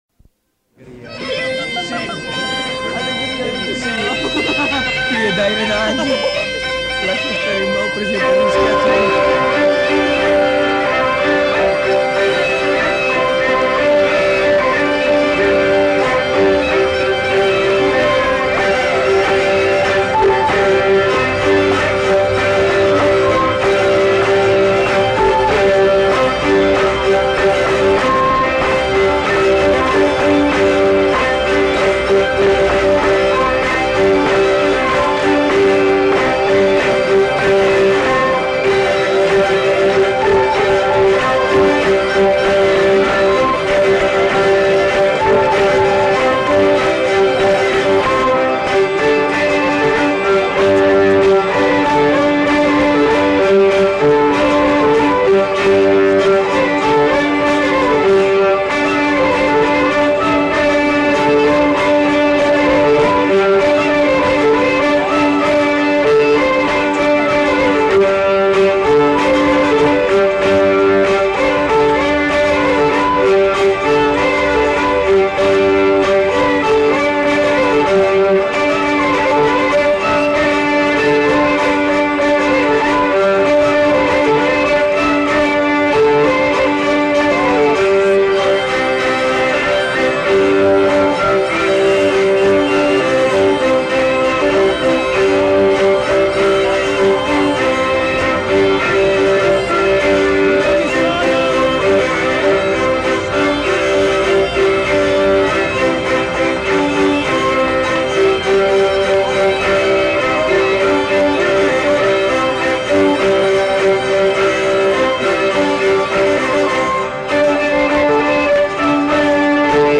Aire culturelle : Pays d'Oc
Lieu : Pinerolo
Genre : morceau instrumental
Instrument de musique : violon ; vielle à roue ; boha
Danse : congo